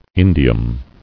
[in·di·um]